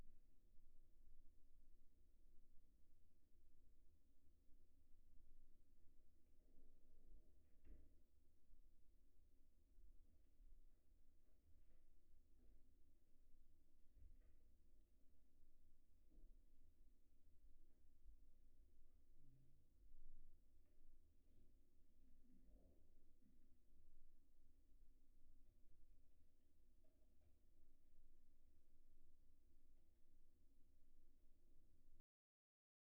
This test uses audio samples taken from three everyday scenarios: a busy street, an office, and an airplane cabin.
office-noise.wav